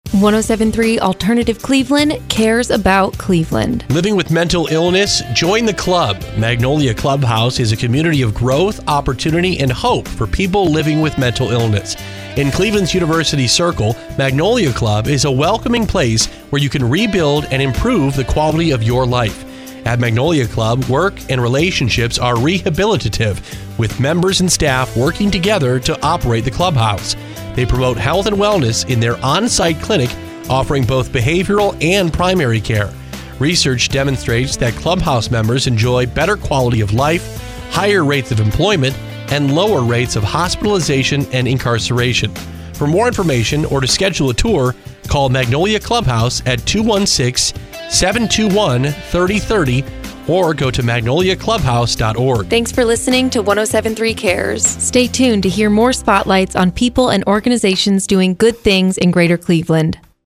Magnolia Clubhouse was recently featured in a public awareness spot created by our friends at 107.3 FM - Alternative Cleveland.